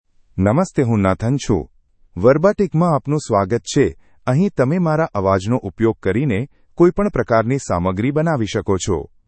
MaleGujarati (India)
NathanMale Gujarati AI voice
Nathan is a male AI voice for Gujarati (India).
Voice sample
Listen to Nathan's male Gujarati voice.
Nathan delivers clear pronunciation with authentic India Gujarati intonation, making your content sound professionally produced.